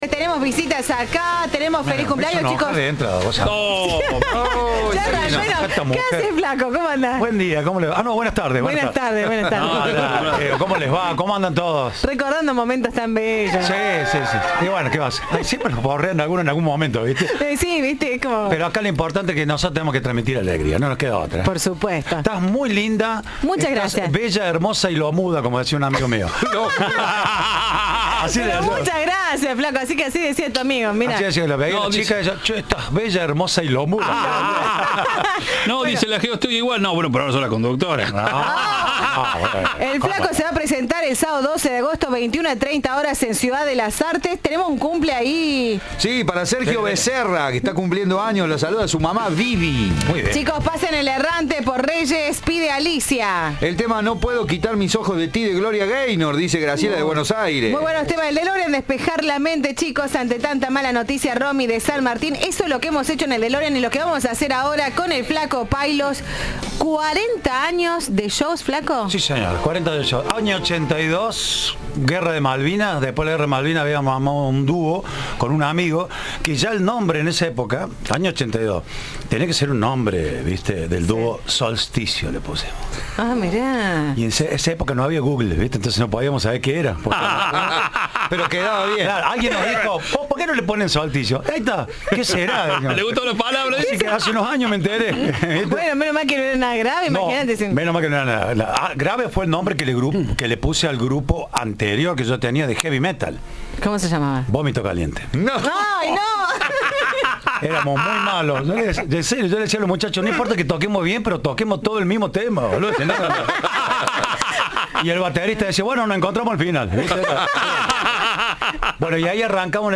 Guitarra en mano, el humorista divirtió a la audiencia de Cadena 3 con lo mejor de su repertorio. Se presentará este sábado 12 de agosto en el Teatro Ciudad de las Artes.